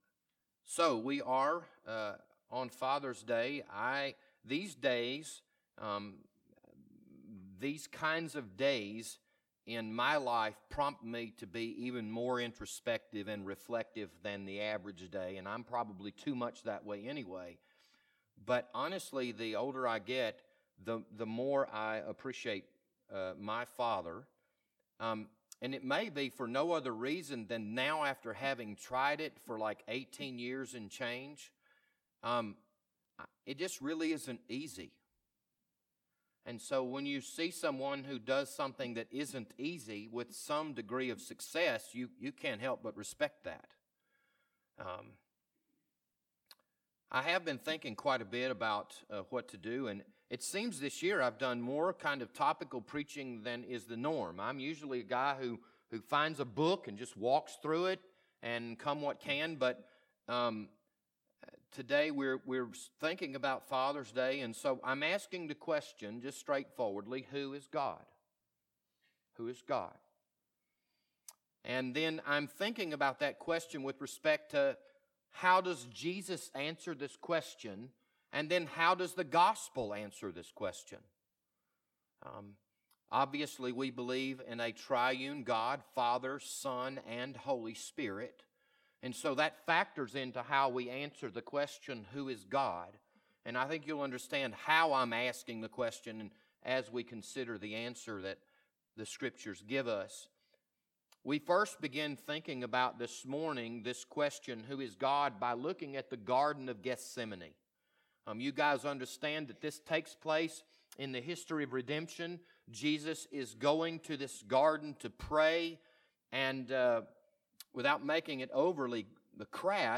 2018 in Sunday Morning Sermons